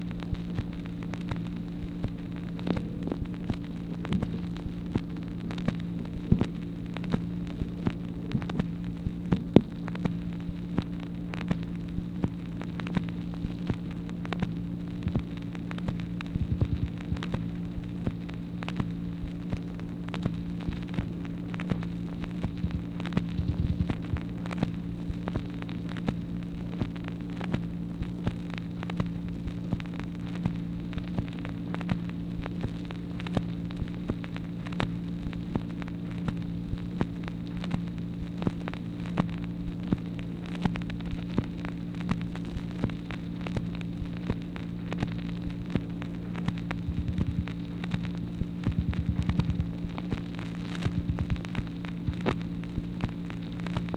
MACHINE NOISE, May 11, 1964